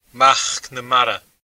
Pronunciation [əɲ ˈtʲʰelan ˈs̪kʲi.anəx]